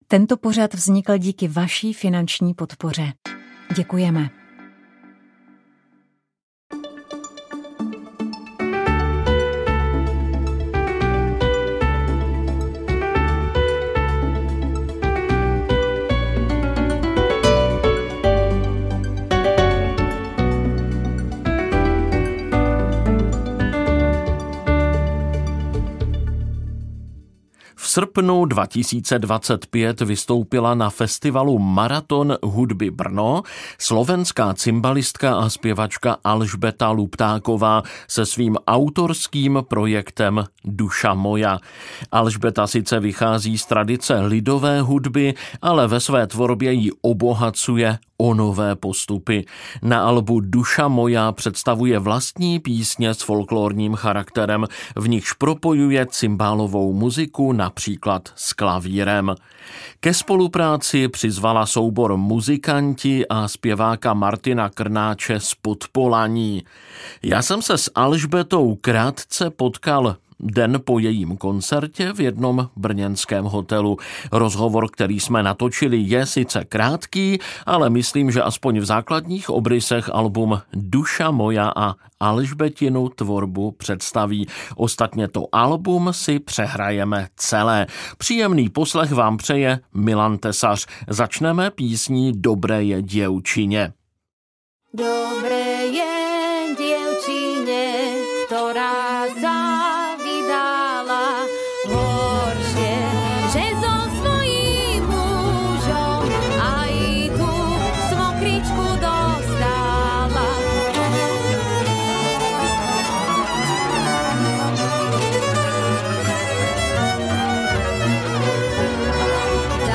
Jak se vám líbí – Rozhovor